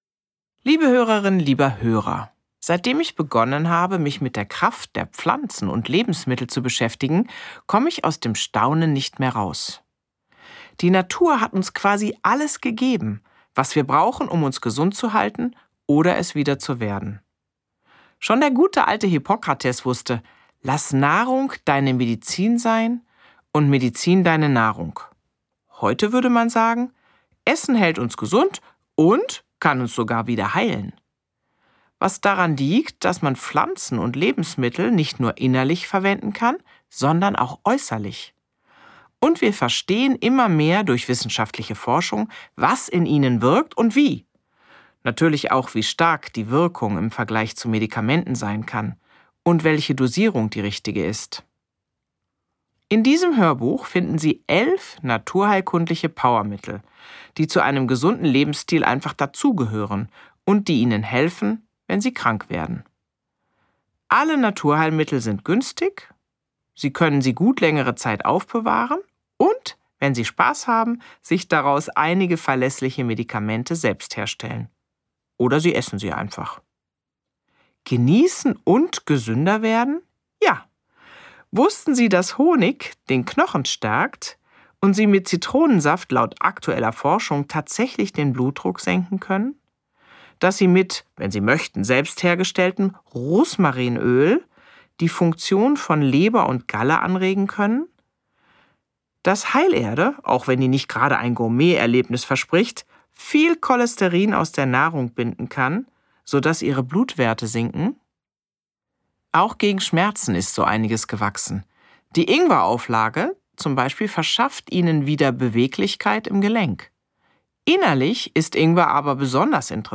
11 Naturheilmittel ersetzen eine Apotheke Gelesen von: Dr. med. Franziska Rubin